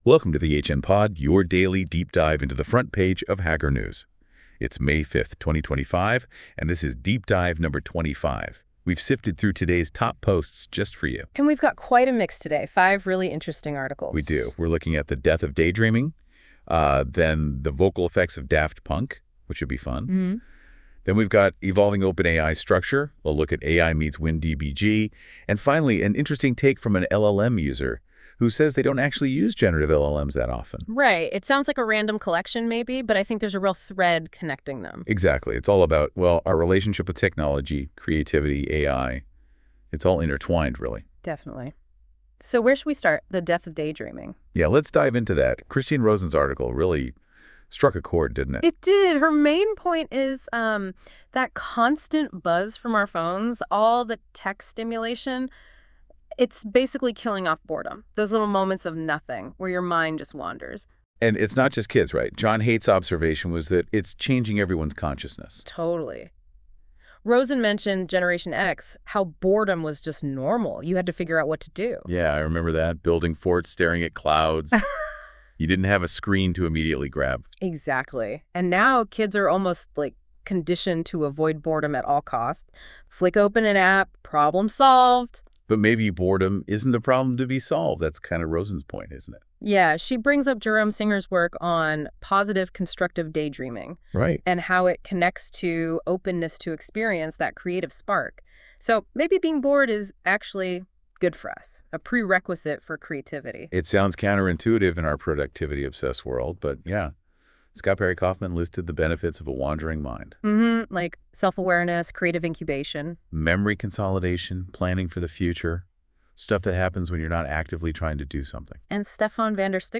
This episode is generated by 🤖 AI.